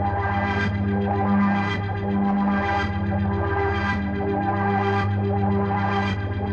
Index of /musicradar/dystopian-drone-samples/Tempo Loops/110bpm
DD_TempoDroneE_110-G.wav